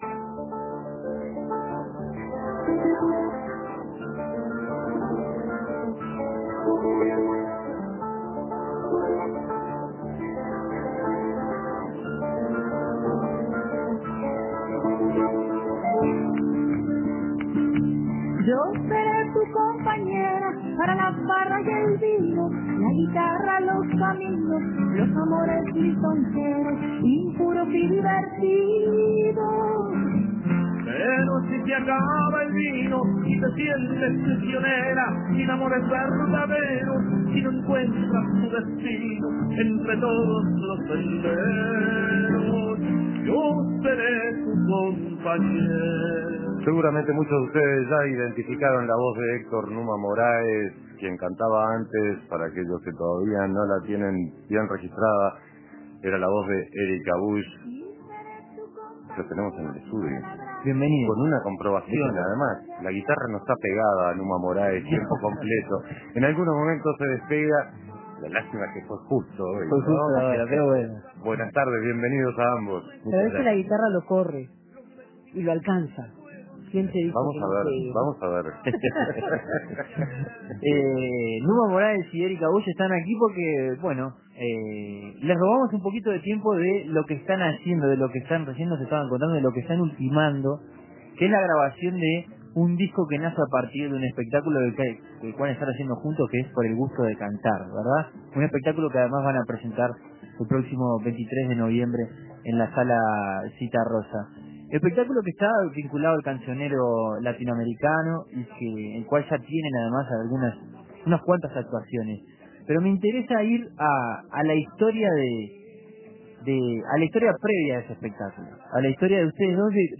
Con guitarras y canciones